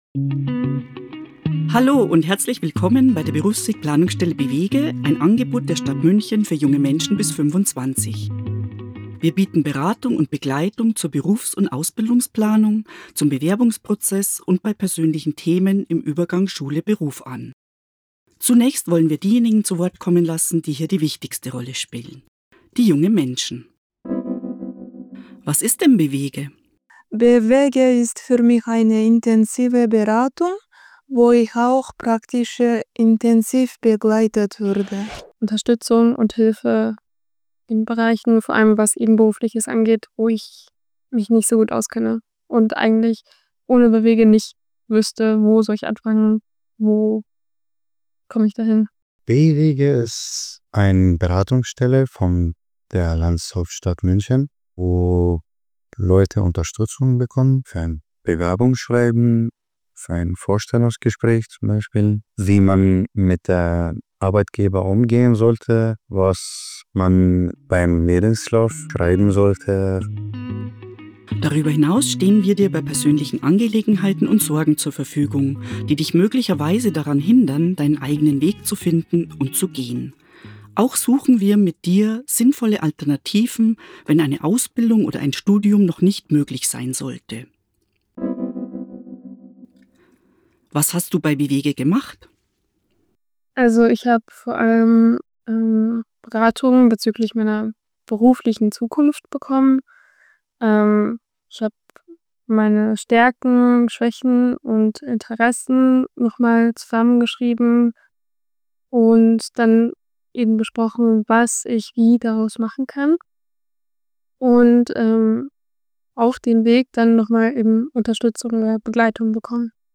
“b-wege lohnt sich” – unsere Ratsuchenden zu b-wege
Im neuen Podcast haben wir diejenigen zu Wort kommen lassen, die bei b-wege die wichtigste Rolle spielen: die jungen Menschen.